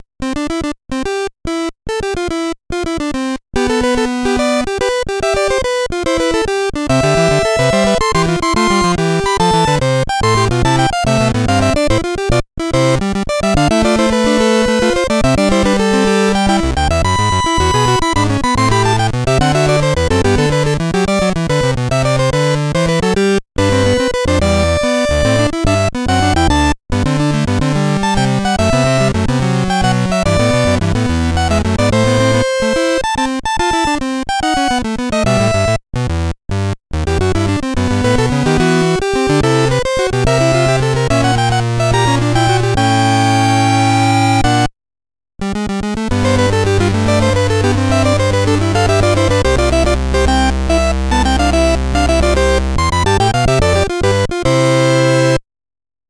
A happy sailor's title melody